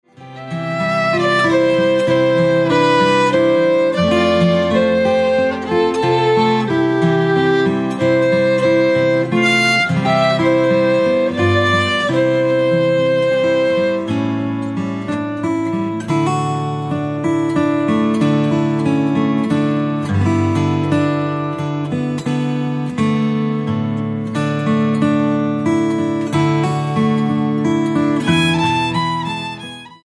Traditional hymn instrumentals for guitar, violin and flute